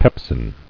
[pep·sin]